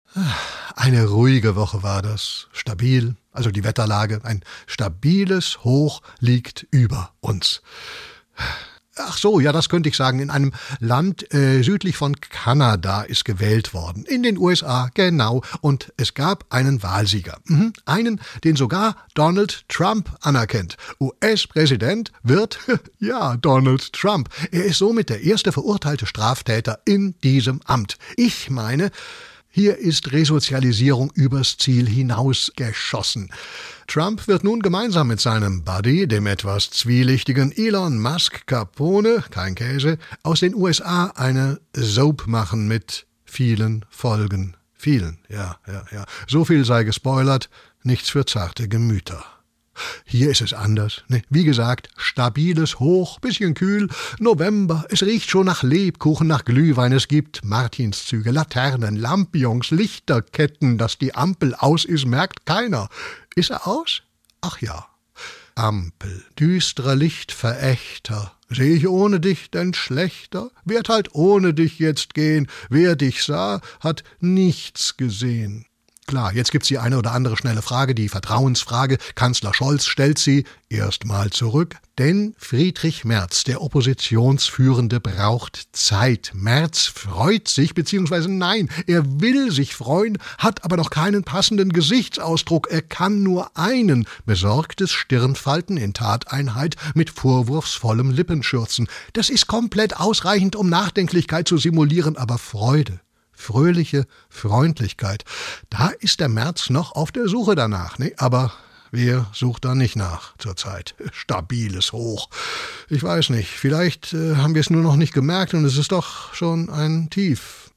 SWR3 Comedy Reuschs Wochenrückblick